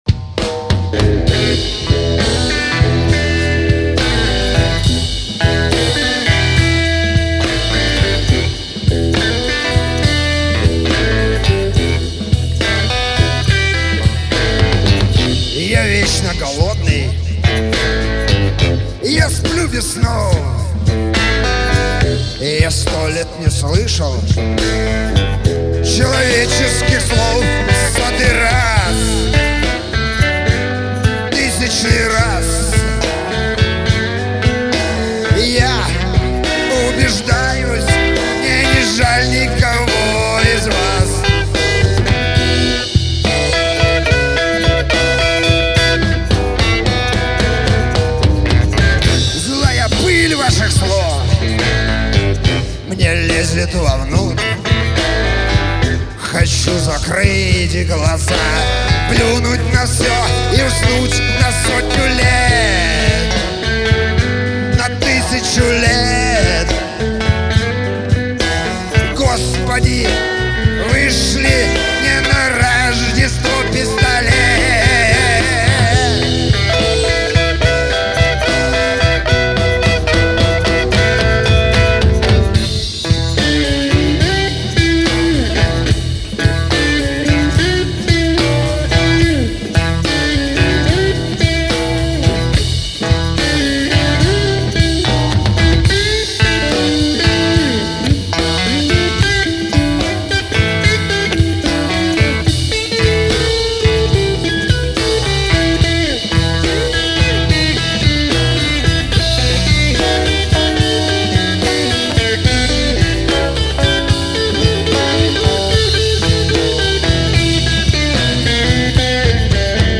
Потому не судите строго-это не студийные вылизанные записи-это как в жизни- по всякому.
НЕОТЕСАНЫЙ БЛЮЗ на злобу дня."Последняя миля"